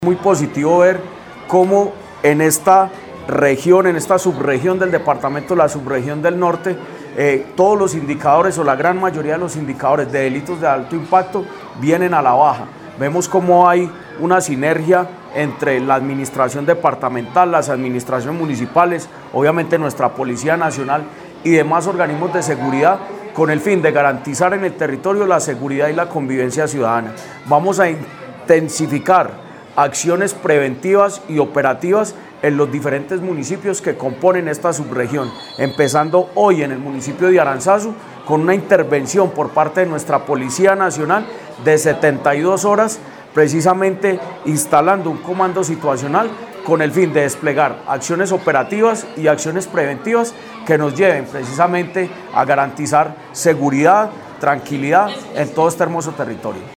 Jorge Andrés Gómez Escudero, secretario de Gobierno de Caldas
Jorge-Andres-Gomez-Escudero-secretario-dr-Gobierno-de-Caldas-Aranzazu.mp3